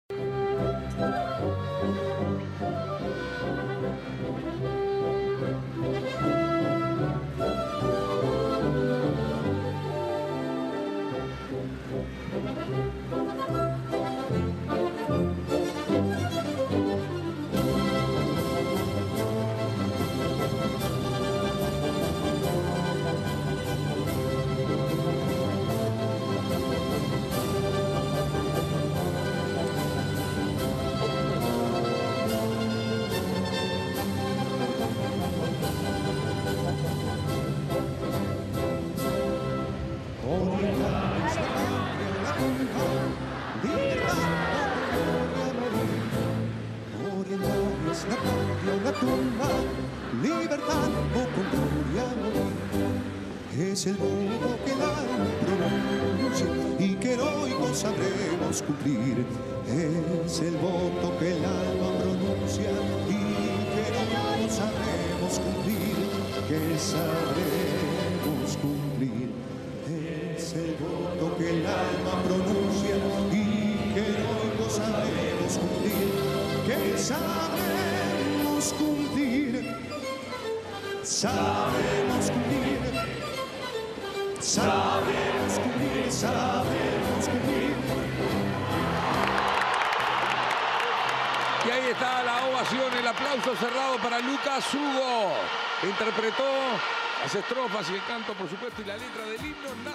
En Vivo [Estadio Centenario]